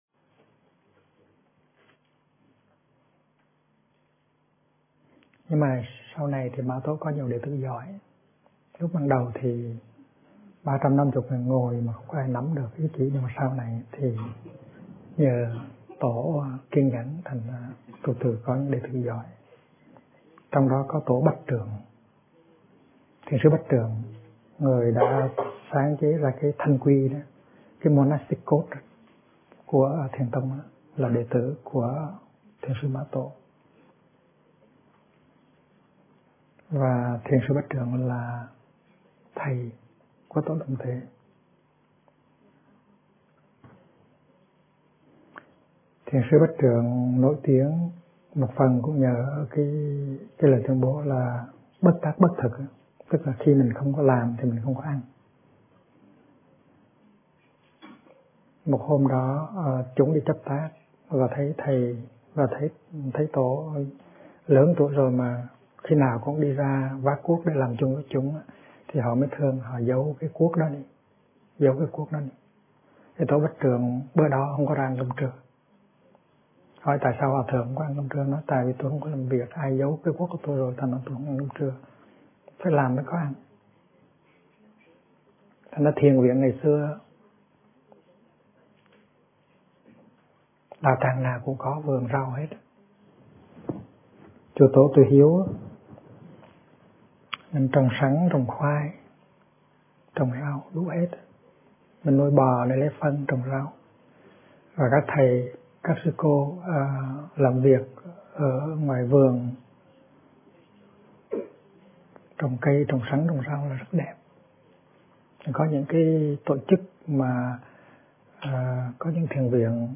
Kinh Giảng Sự Trao Truyền Của Chư Tổ - Thích Nhất Hạnh